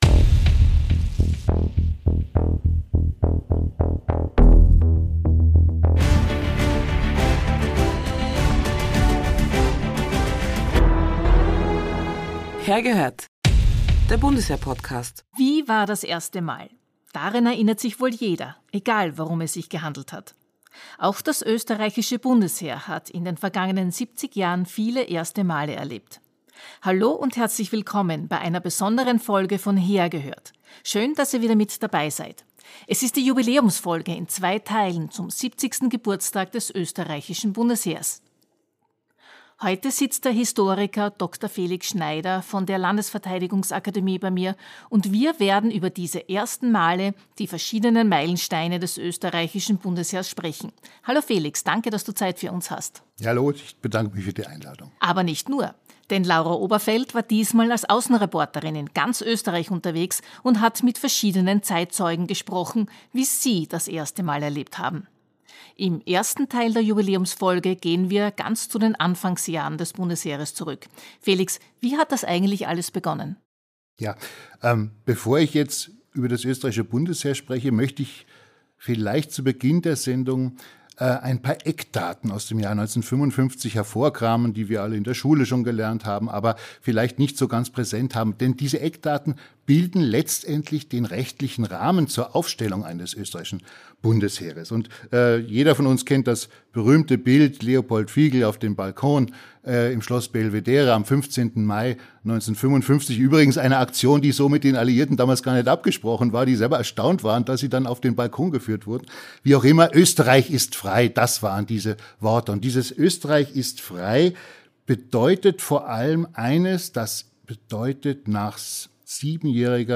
Im ersten Teil der Jubiläumsfolge stehen die Anfangsjahre mit ihren großen Bewährungsproben im Mittelpunkt. Zeitzeugen erzählen von ihrem ganz persönlichen „ersten Mal“.